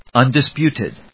un・dis・put・ed /`ʌndɪspjúːṭɪd/